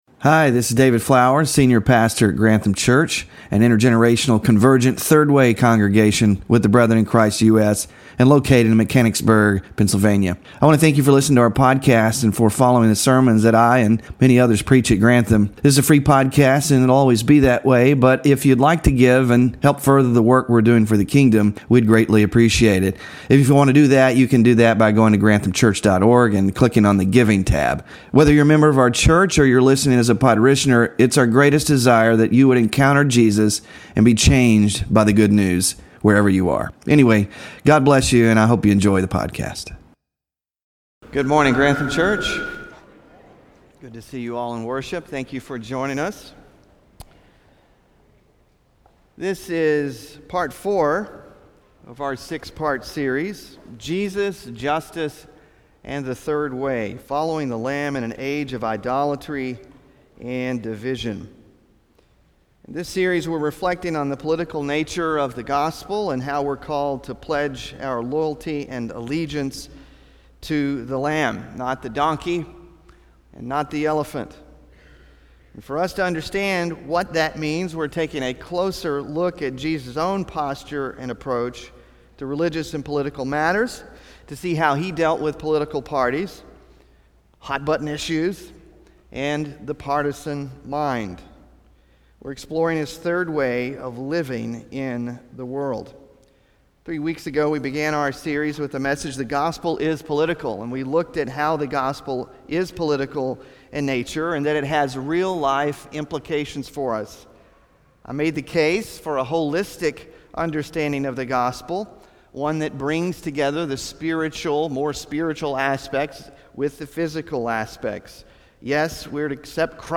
THE THIRD WAY FOR EXTREME DAYS OF GOD SERMON SLIDES (4th OF 6 IN SERIES) SMALL GROUP DISCUSSION QUESTIONS (10-27-24) BULLETIN (10-27-24)